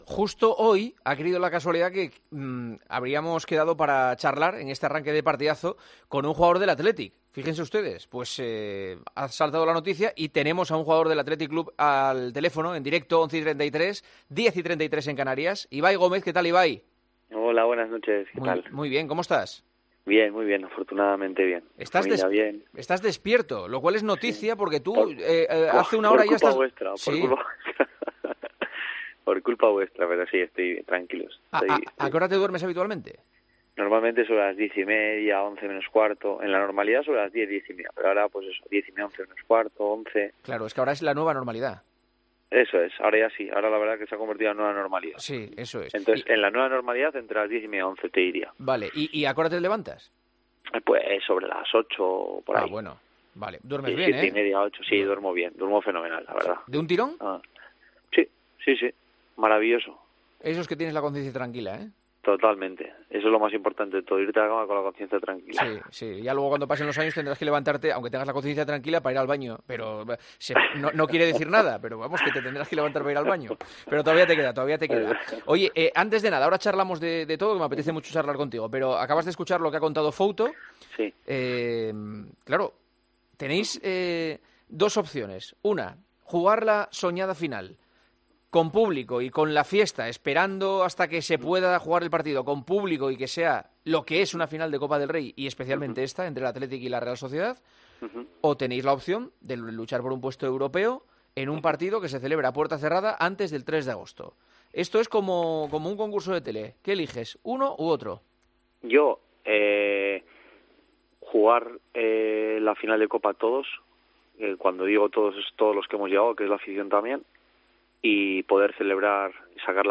AUDIO - ESCUCHA LA ENTREVISTA A IBAI GÓMEZ, EN EL PARTIDAZO DE COPE